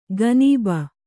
♪ ganība